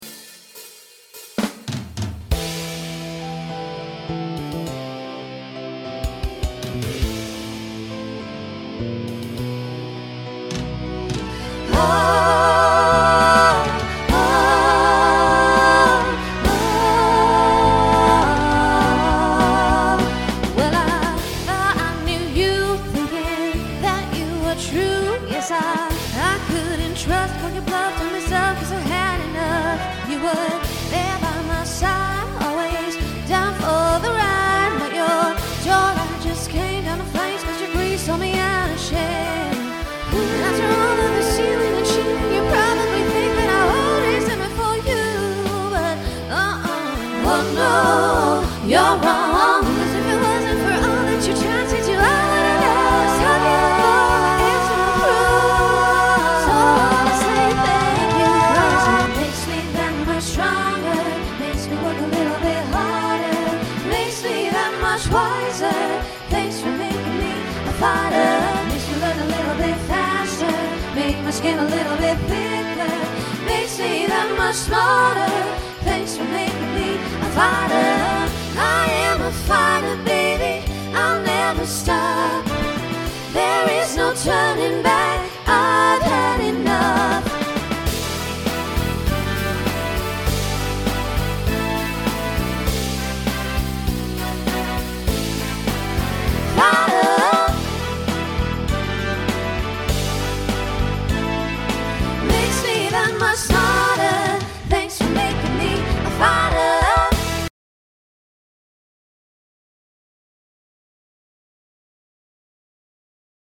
Genre Pop/Dance , Rock Instrumental combo
Transition Voicing SSA